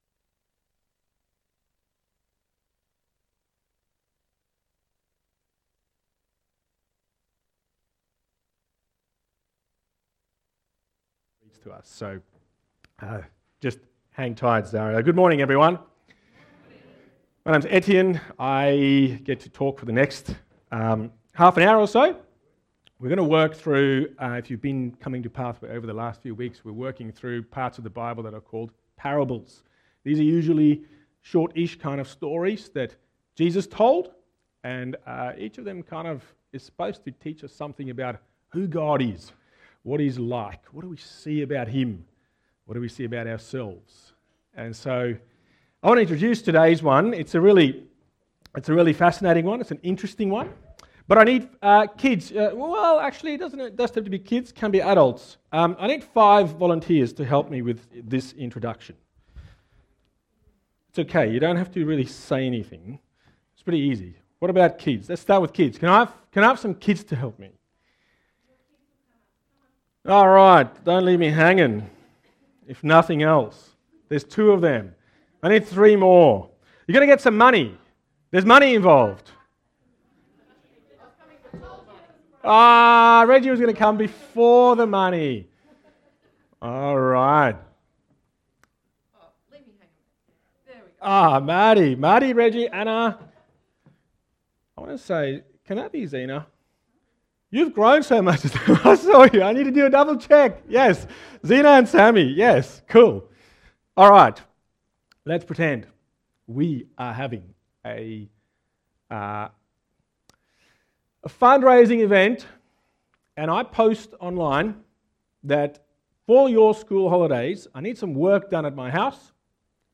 Text: Matthew 20: 1-16 Sermon